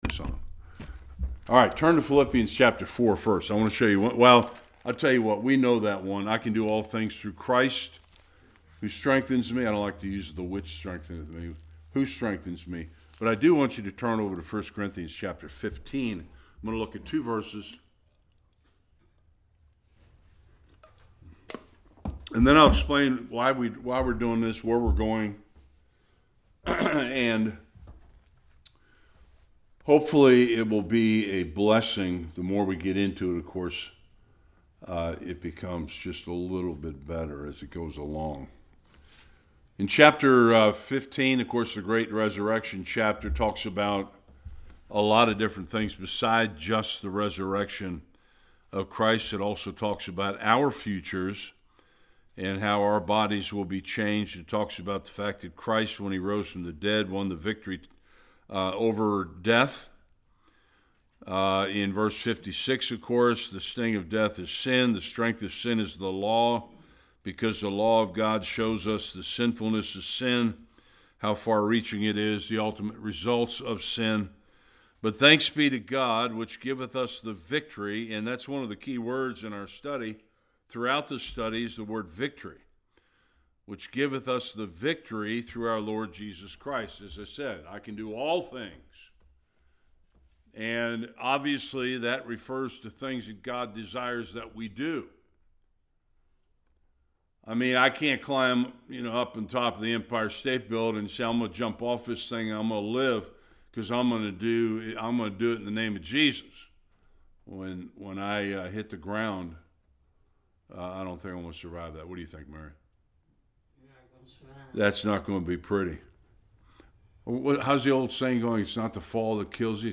57-58 Service Type: Bible Study Too many give up and quit when things get difficult.